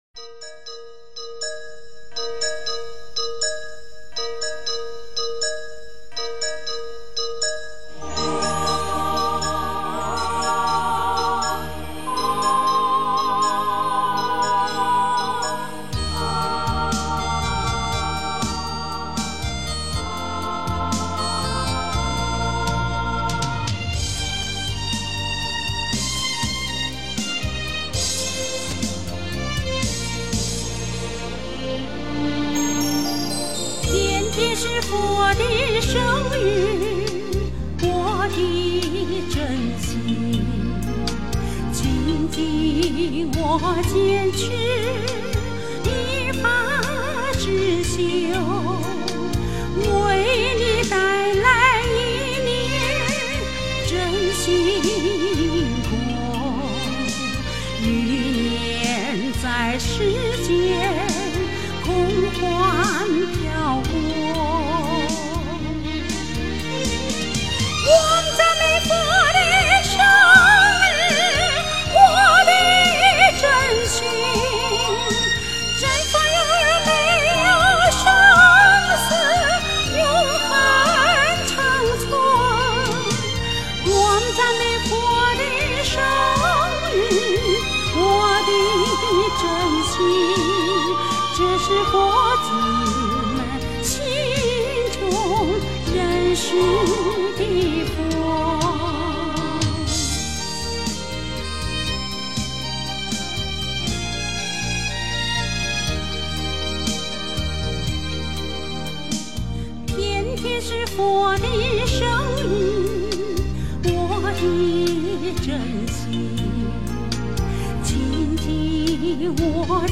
天天是佛的生日 诵经 天天是佛的生日--佛教音乐 点我： 标签: 佛音 诵经 佛教音乐 返回列表 上一篇： 随愿 下一篇： 同心同德 相关文章 人在世间--佛教音乐 人在世间--佛教音乐...